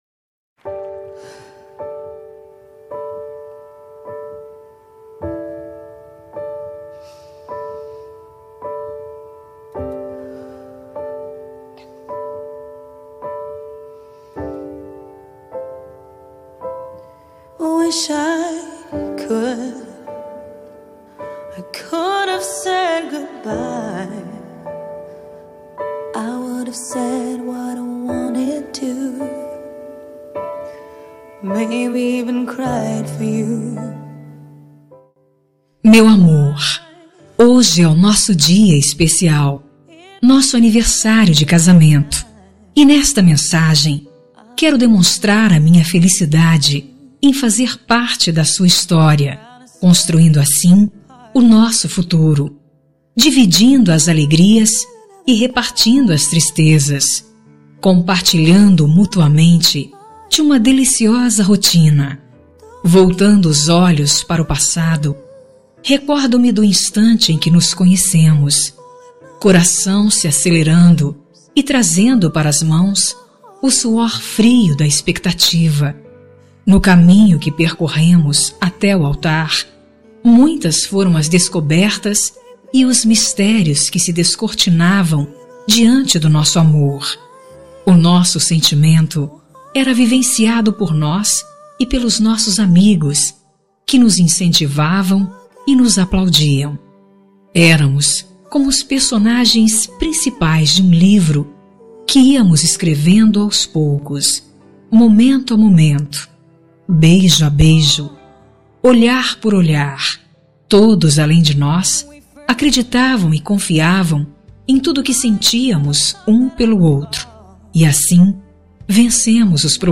Aniversário de Casamento – Voz Feminina – Cód: 8107 – Linda.